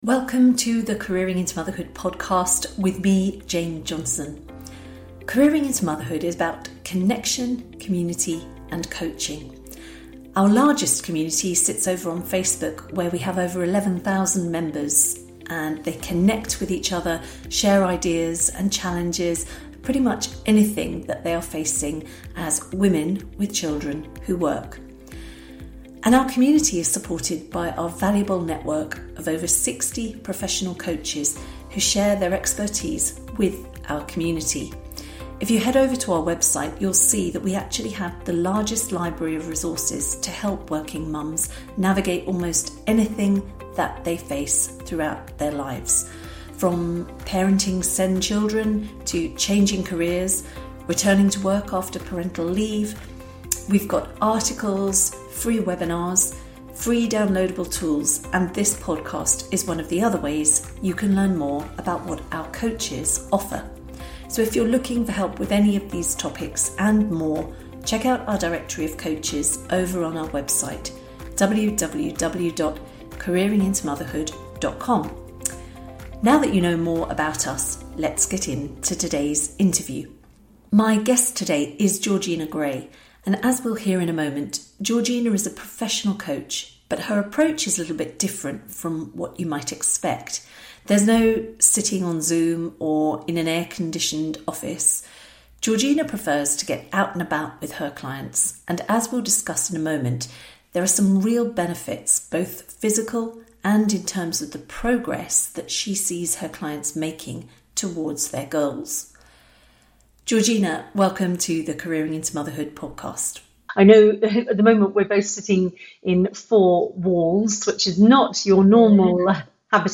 In this enlightening conversation